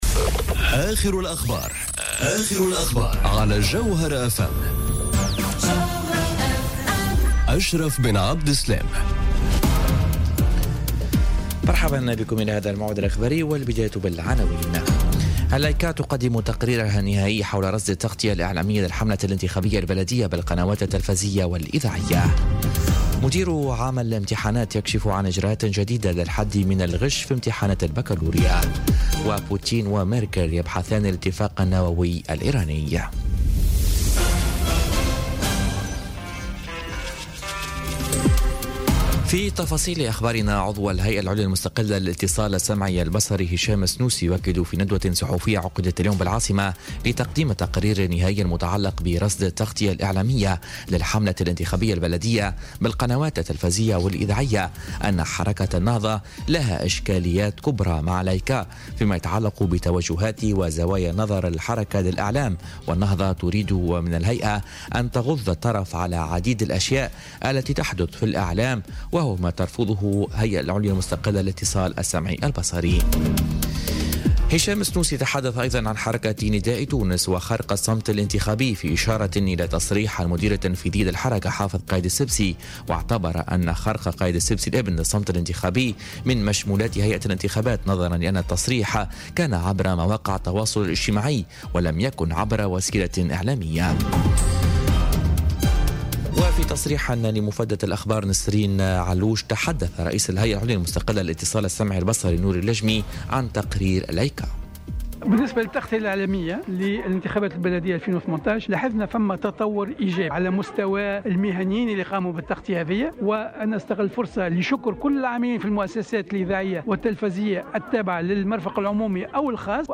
نشرة أخبار منتصف النهار ليوم الجمعة 11 ماي 2018